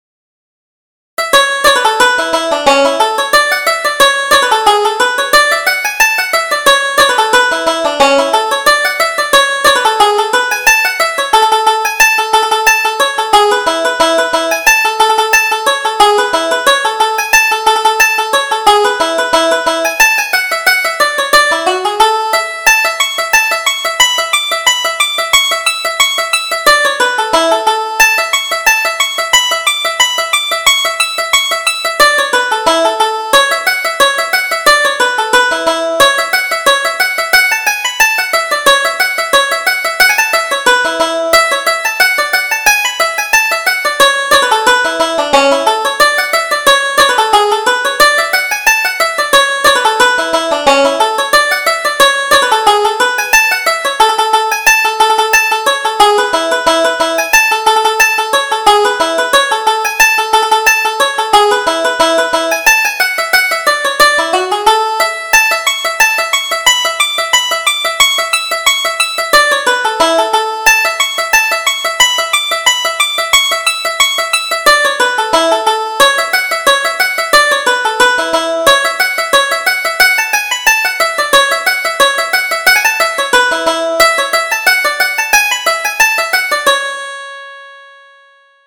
Reel: The Contradiction